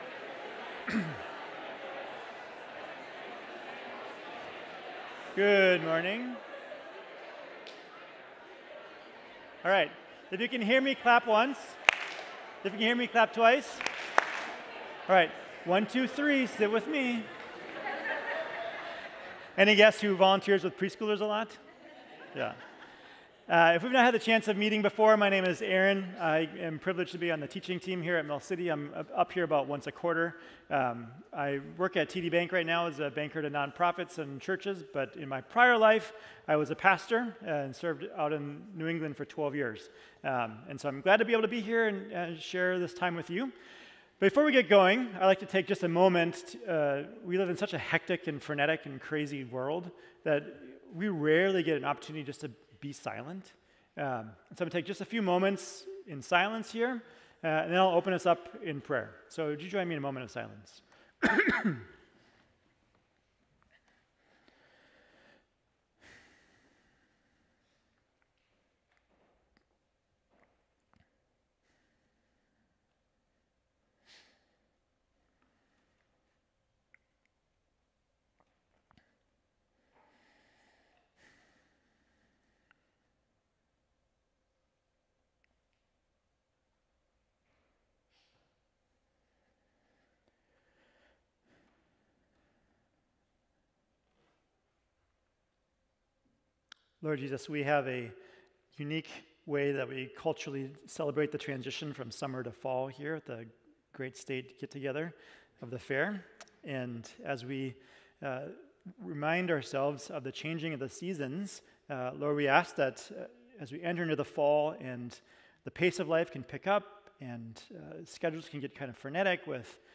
Mill City Church Sermons Seek Out Belonging: Devoted to Community Aug 28 2023 | 00:32:09 1x 00:00 / 00:32:09 Subscribe Share RSS Feed Share Link Embed